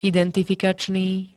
identifikačný [-d-t-] -ná -né príd.
Zvukové nahrávky niektorých slov